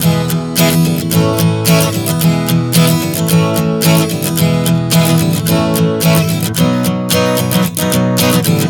Prog 110 E-A-B.wav